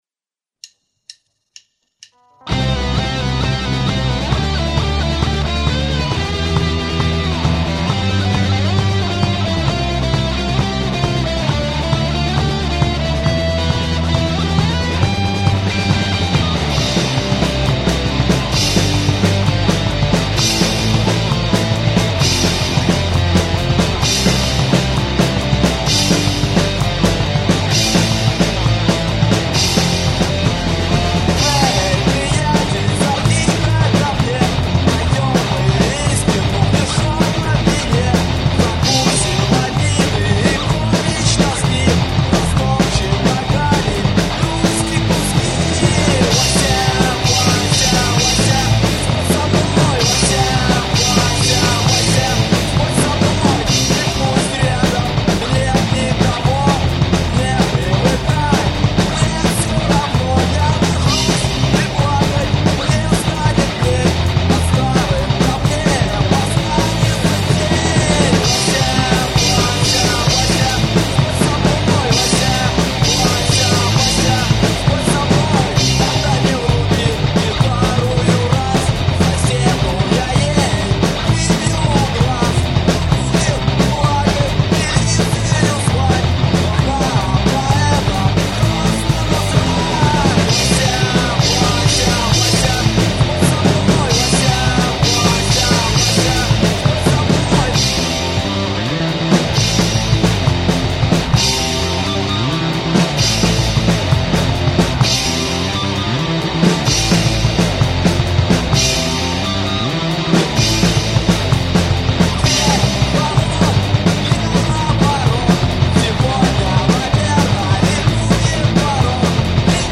ïèñàëè íà òî÷êå. ñíèìàëè ñîâåðøåííî ãàëèìûìè 4 ìèêðîôîíàìè. ïîòîì êîíå÷íî îáðàáîòêà, íî áåç ðåâåðîâ. îäèí ìèê â áî÷êó. îäèí íà ðàáî÷èé, îäèí ïîä íåãî. äâà îâåðõåäà íà îäíîé ñòîéêå ïîä óãëîì 90 ãð. ìèíóñ òàêîé çàïèñè îâåðõåäîâ - ñèëüíî ëåçåò êðýø è åãî íå óìåíüøèòü. ñòîåê ó íàñ ëèøíèõ íå áûëî. âñ¸ êðîìå áàðàáàíîâ çàïèñàëè äîìà.
íî â ïðèíöèïå ýòî óæå äîñòîéíàÿ äåìî çàïèñü è â êëóá å¸ óæå ìîæíî íåñòè.